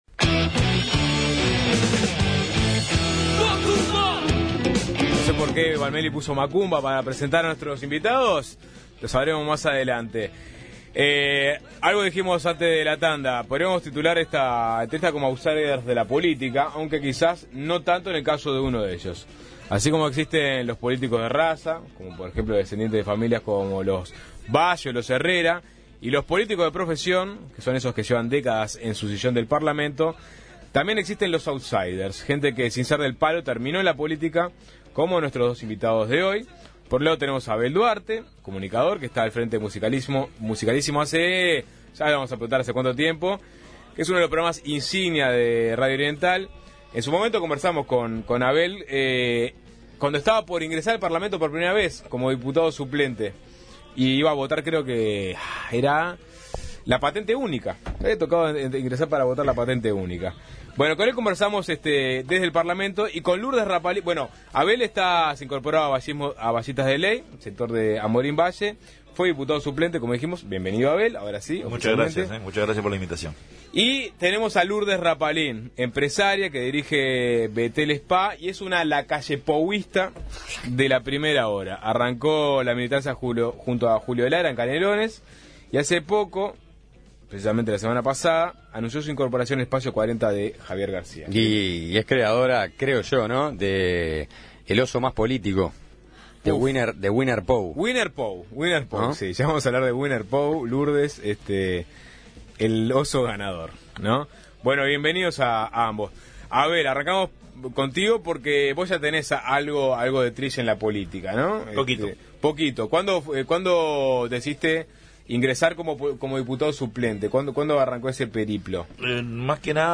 Nos visitaron en Suena Tremendo y charlaron sobre sus inicios en política y los problemas de la actualidad.